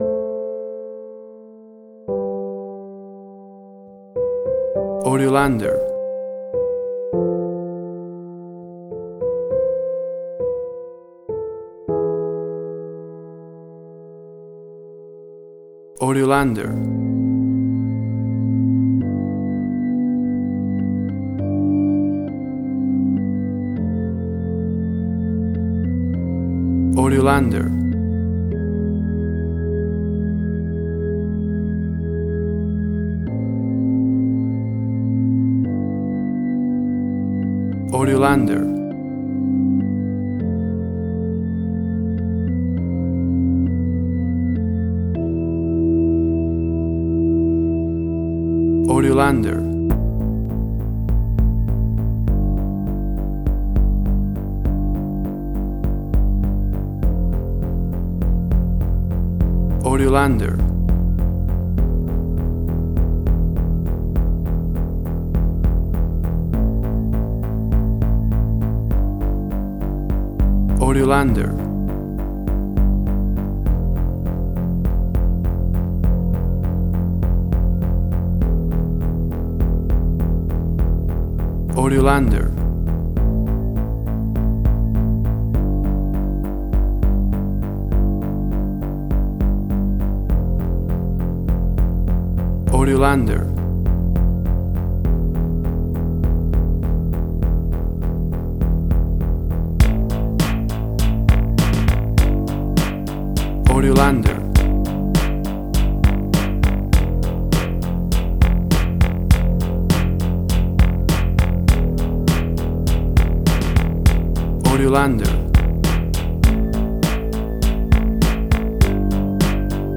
Suspense, Drama, Quirky, Emotional.
WAV Sample Rate: 16-Bit stereo, 44.1 kHz
Tempo (BPM): 101